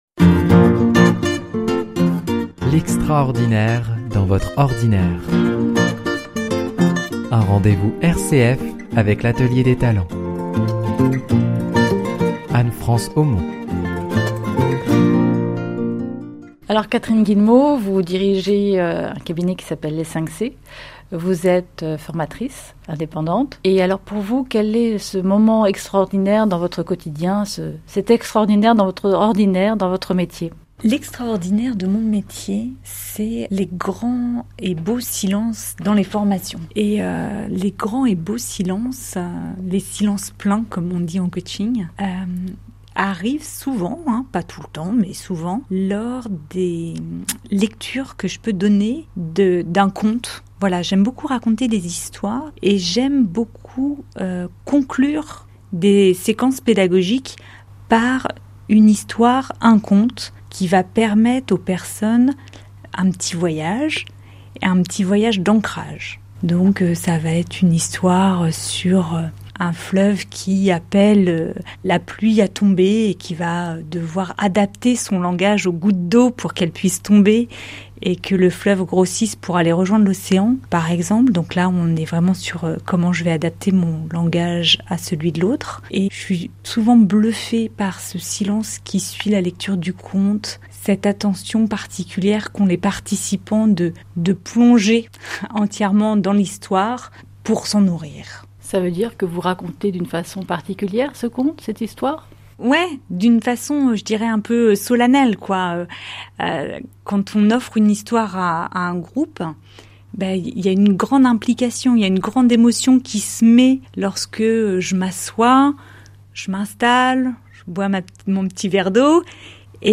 Genre : Radio.